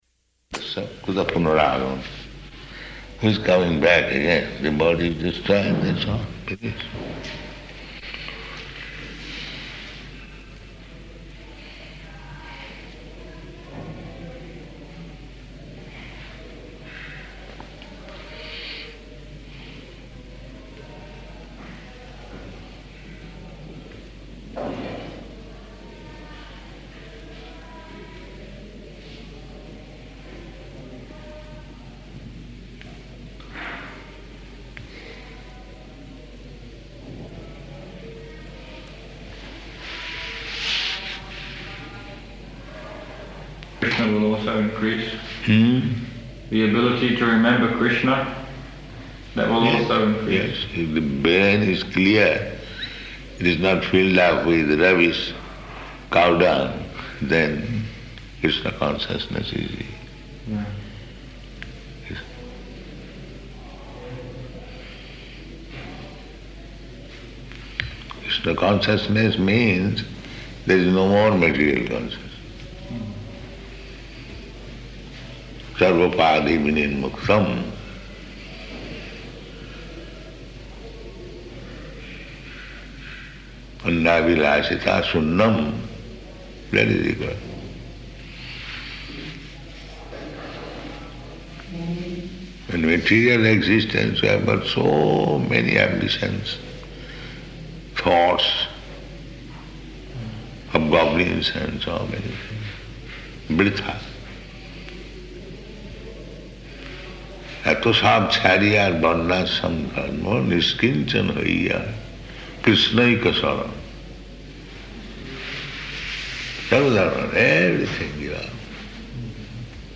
Room Conversation
Room Conversation --:-- --:-- Type: Conversation Dated: November 20th 1976 Location: Vṛndāvana Audio file: 761120R1.VRN.mp3 Prabhupāda: ...kutah punah agamam.